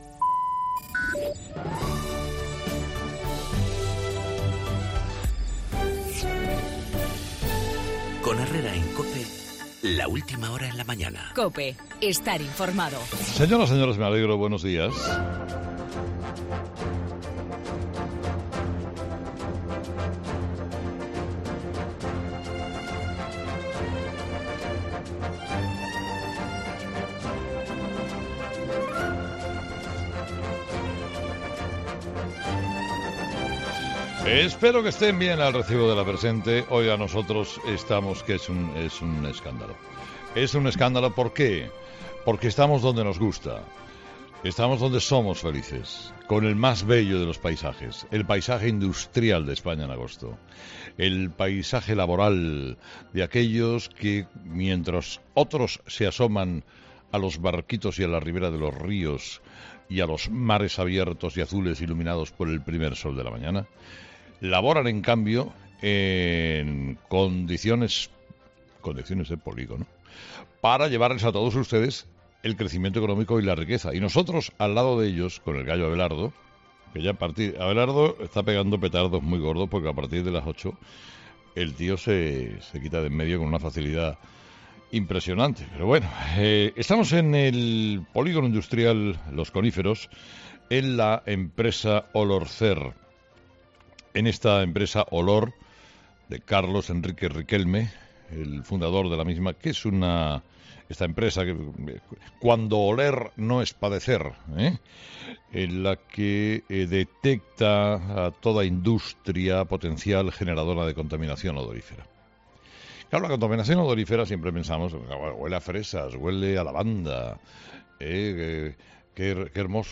Escucha el comentario de Herrera a las 8 del 9 de agosto de 2017